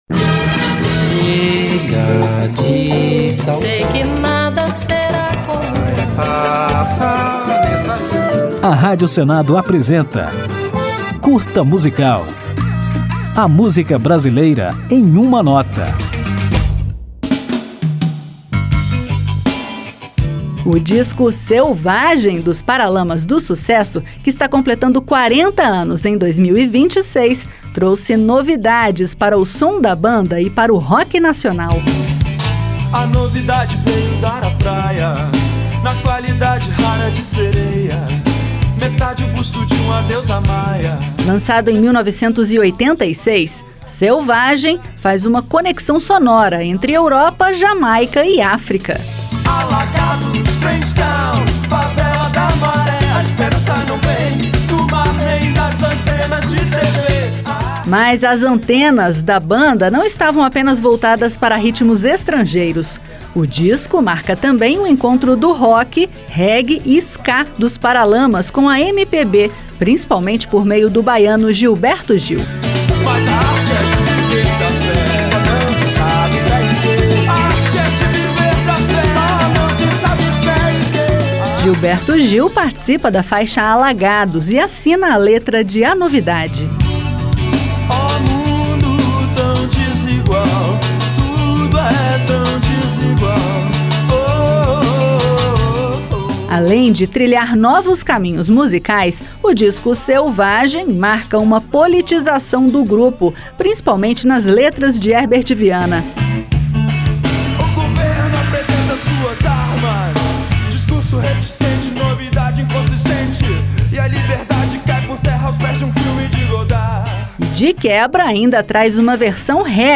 Nele, a banda surgiu com letras politizadas e ainda se aproximou da MPB e de ritmos jamaicanos e africanos. É nele que está o sucesso "Alagados", que toca ao final do programa.